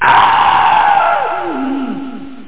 Amiga 8-bit Sampled Voice
scream&echo.mp3